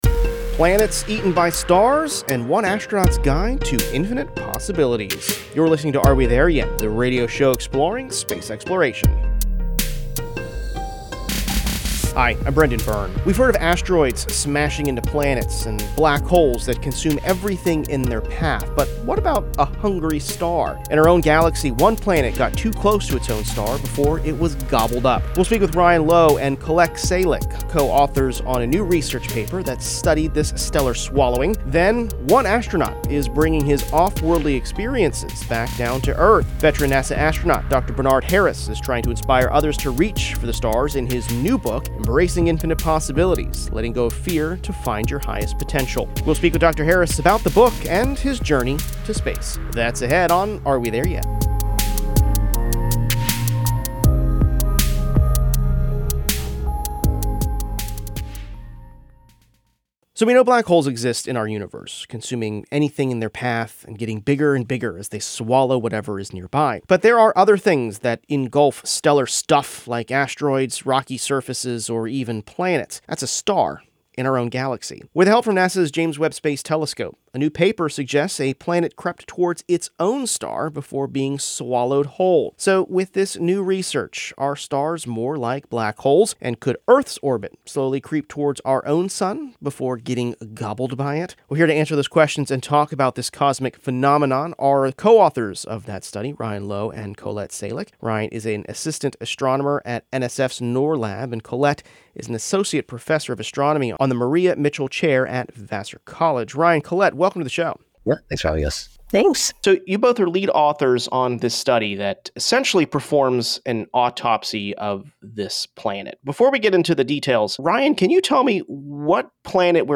Listen to interviews with astronauts, engineers and visionaries as humanity takes its next giant leap exploring our universe.